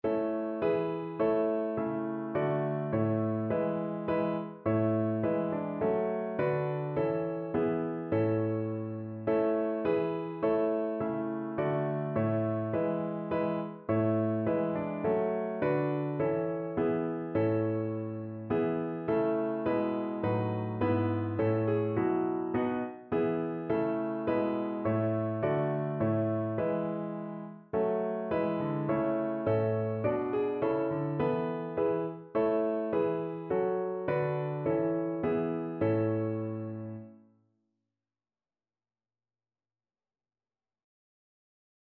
Notensatz 1 (4 Stimmen gemischt)
• gemischter Chor mit Akk.